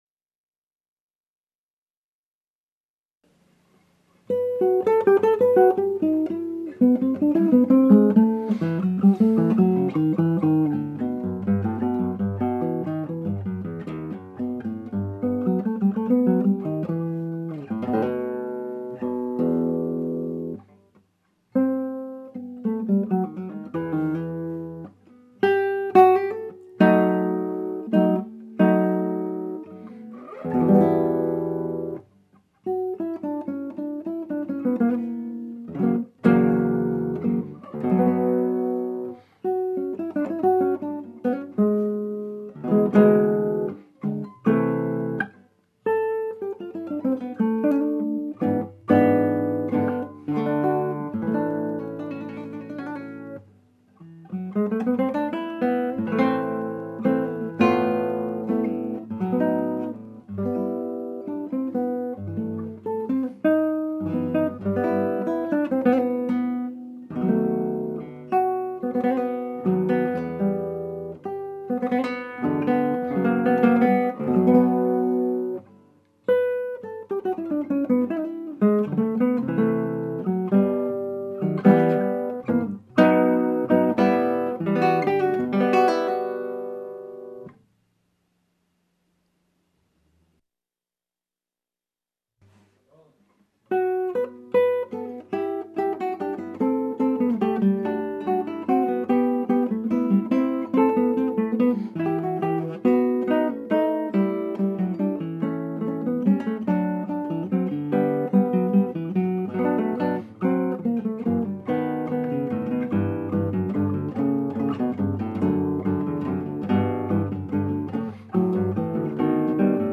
solo guitar pieces